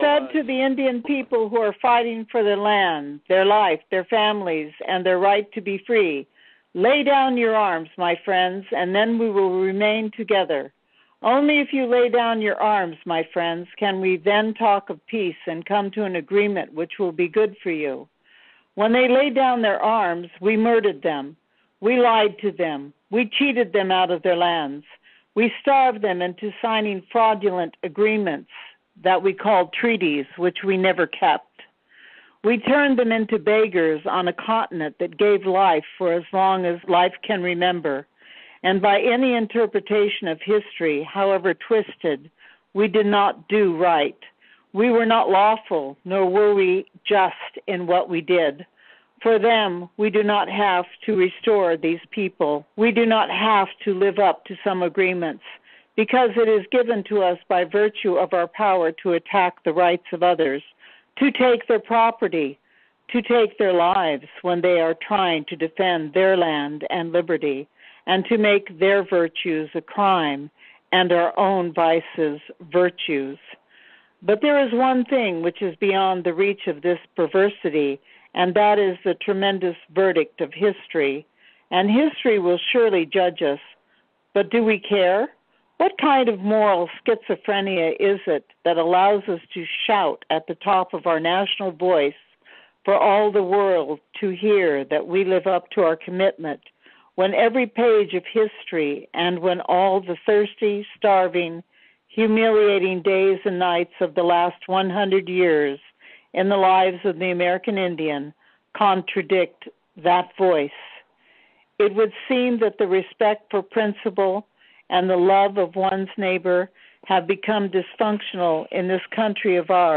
In 2018 on a radio program, Sacheen Littlefeather read her entire FIVE minute speech.
Here is Sacheen Littlefeather reading the entire, intended speech